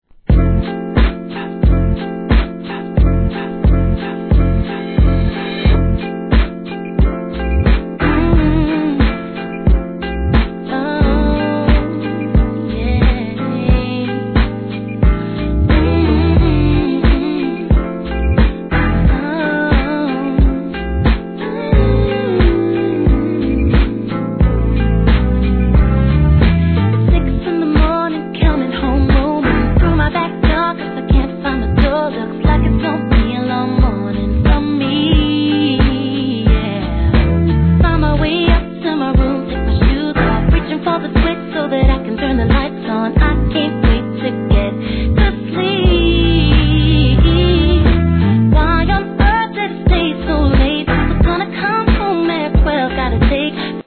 HIP HOP/R&B
心地よく刻むBEATとドリーミーな上音でキュートなヴォーカル♪